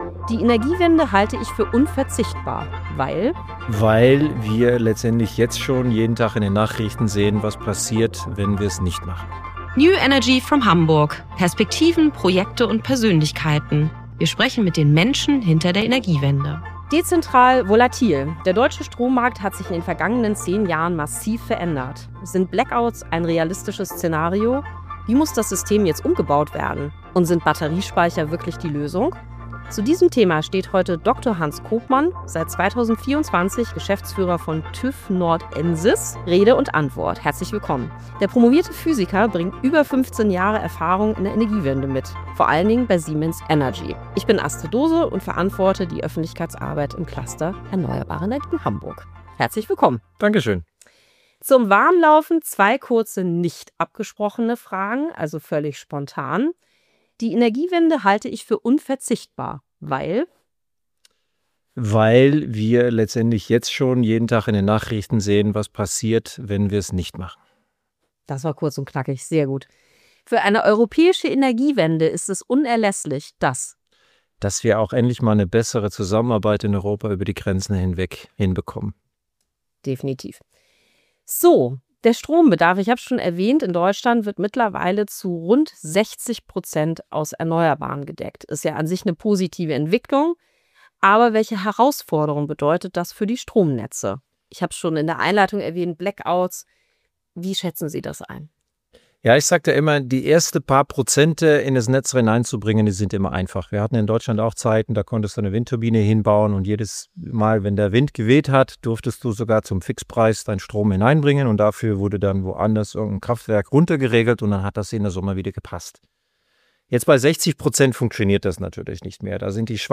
Ein Gespräch über Netzstabilität, Dunkelflauten, den Einsatz von Speichern und die Frage, welche politischen und technischen Rahmenbedingungen jetzt entscheidend sind, damit die Energiewende zuverlässig funktioniert.